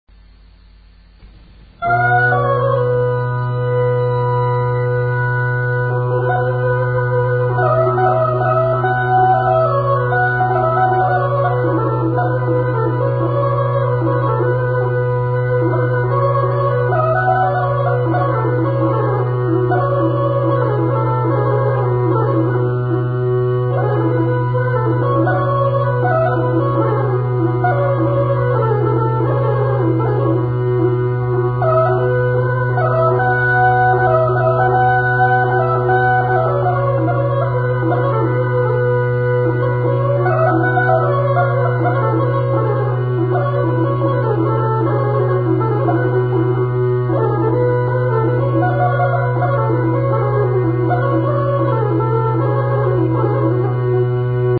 музикална класификация Инструментал
тематика Хороводна (инструментал)
размер Две четвърти
фактура Двугласна
начин на изпълнение Солово изпълнение на гайда
фолклорна област Югоизточна България (Източна Тракия с Подбалкана и Средна гора)
място на записа Гледка
начин на записване Магнетофонна лента